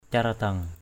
/ca-ra-dʌŋ/ (Khm.) (d.) vương miện có gắn lông công.